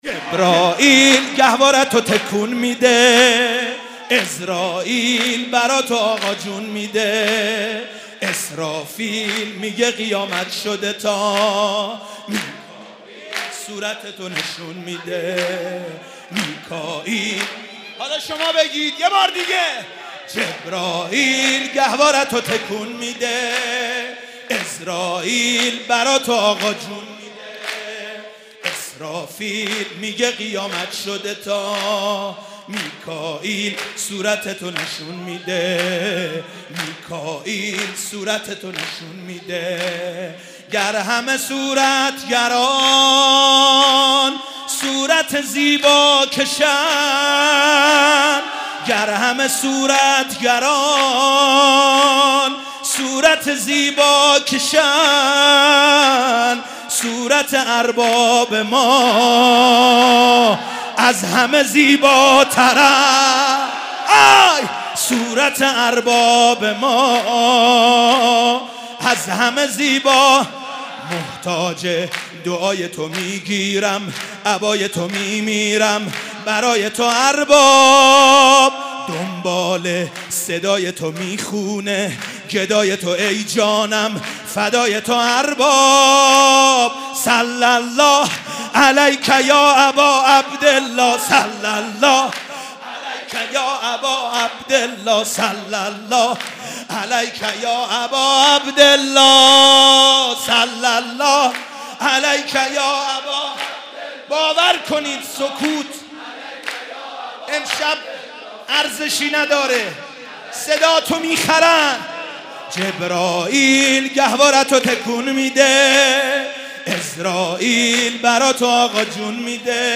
شب میلاد امام حسین (ع)در هیأت آل یاسین قم
سرود
مدح